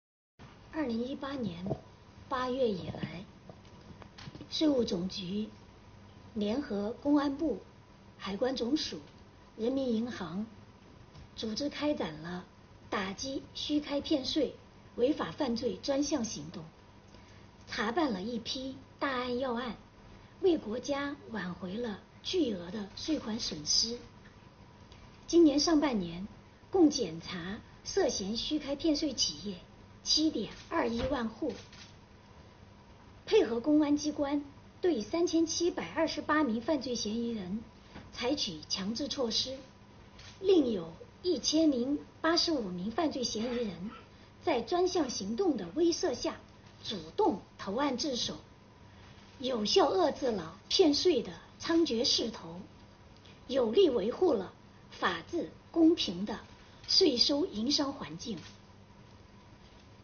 7月26日，国家税务总局召开新闻发布会，就税收大数据反映经济发展情况、税务部门学党史办实事扎实推进办税缴费便利化、打击涉税违法犯罪等内容进行发布并回答记者提问。会上，国家税务总局稽查局副局长付利平介绍了今年上半年税务部门打击虚开骗税违法行为的有关情况。